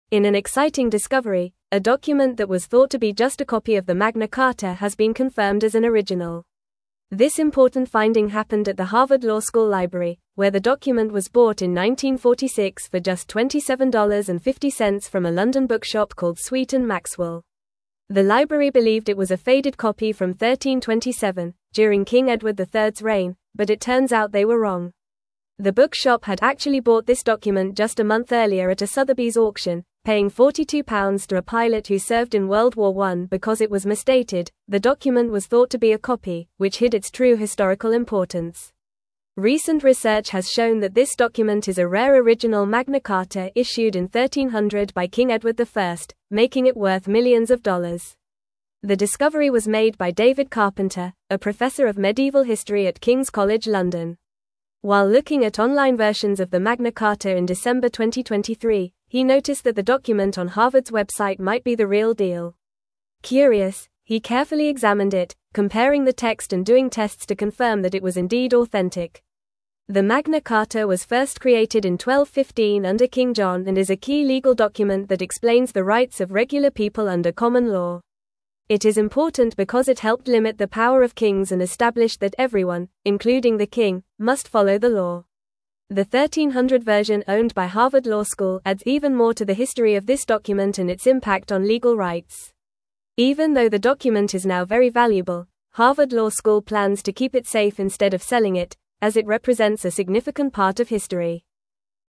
Fast
English-Newsroom-Upper-Intermediate-FAST-Reading-Harvard-Library-Confirms-Original-Magna-Carta-Discovery.mp3